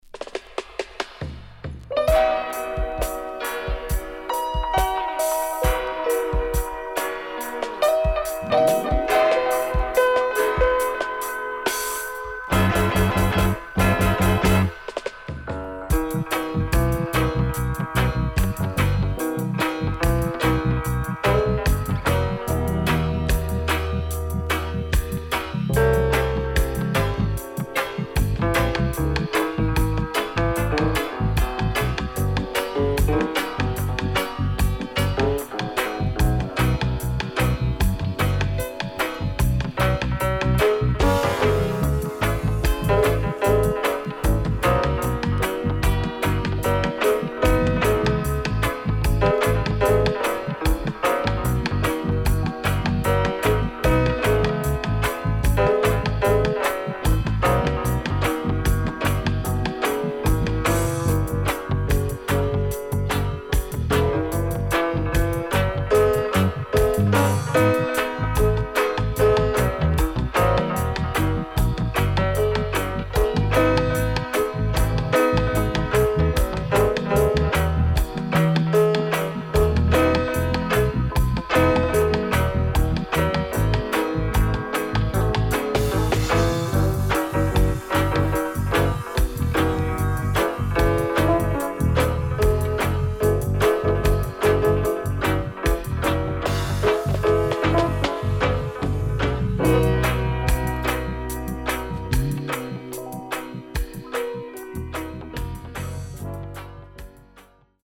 SIDE A:うすいこまかい傷ありますがノイズあまり目立ちません。